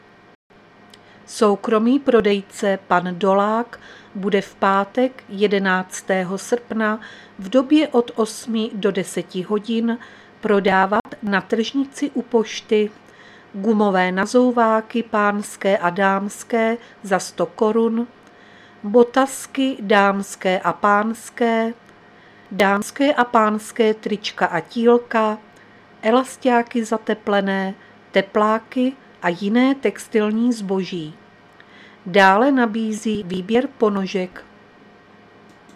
Záznam hlášení místního rozhlasu 10.8.2023